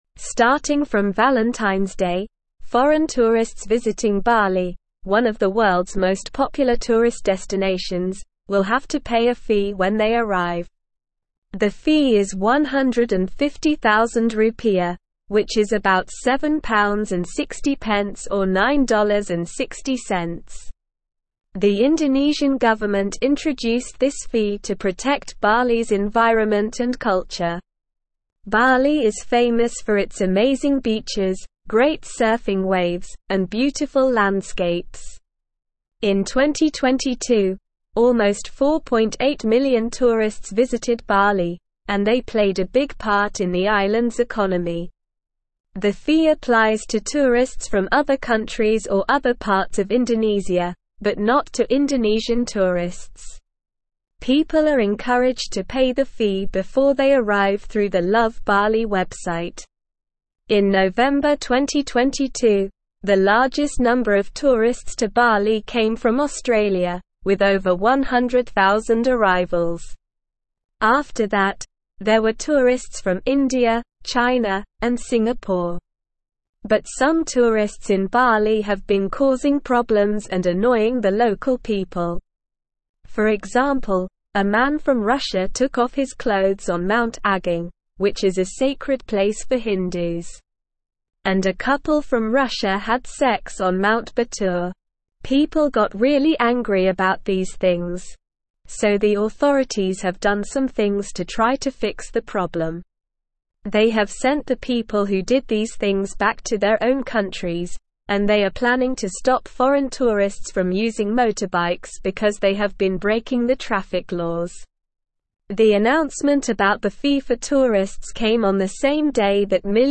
Slow
English-Newsroom-Upper-Intermediate-SLOW-Reading-Bali-Implements-Tourist-Levy-to-Protect-Environment-and-Culture.mp3